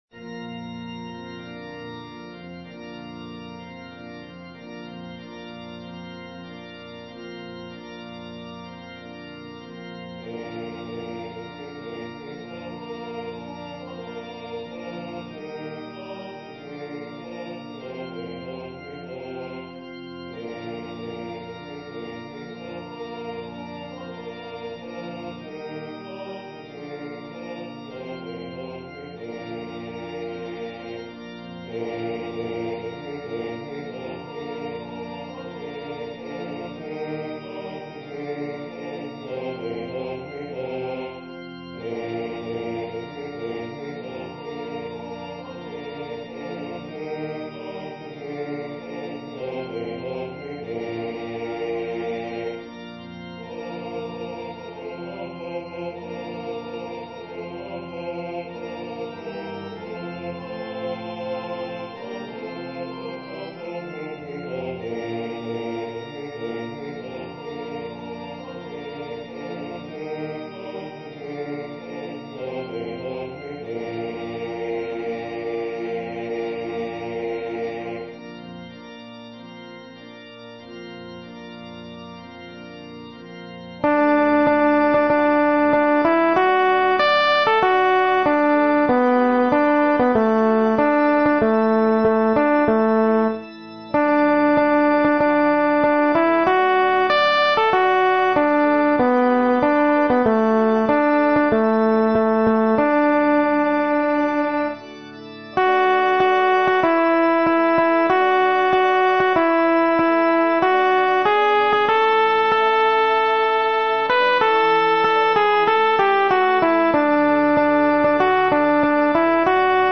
アルト1（フレットレスバス音）